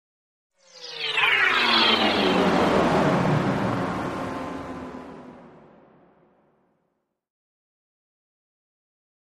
Sweep Electronic Buzz Descending Sweep with Feedback, Shimmer, Tremolo